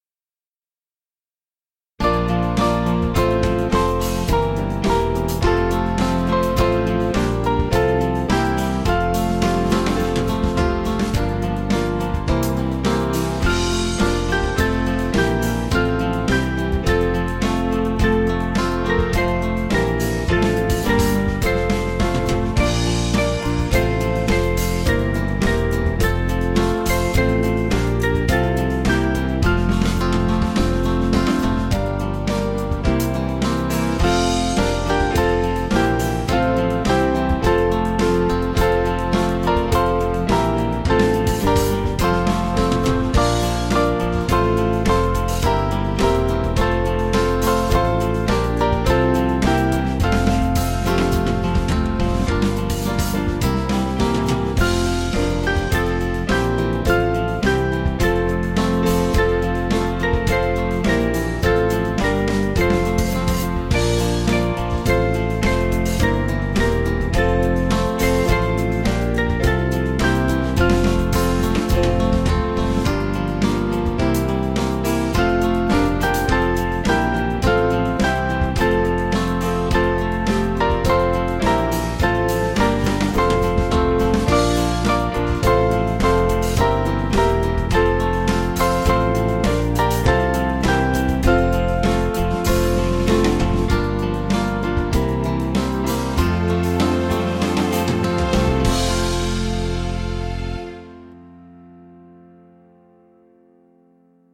Small Band